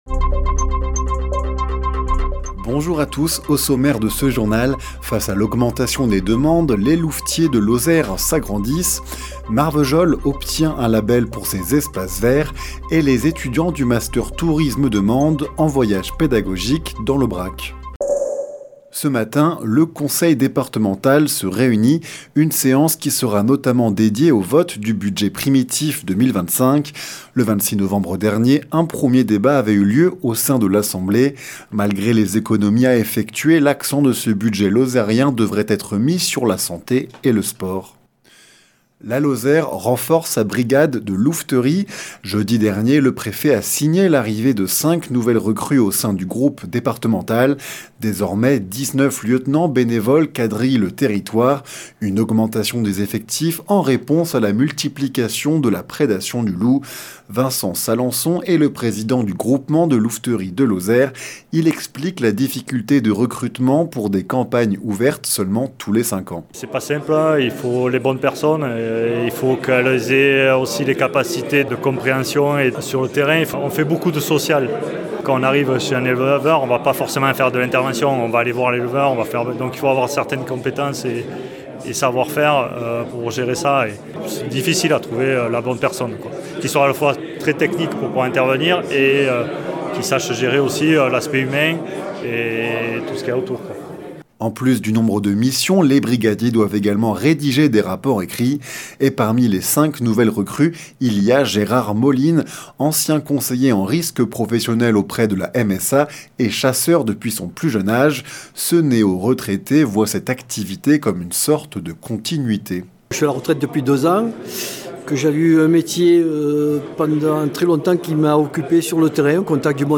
Le journal sur 48FM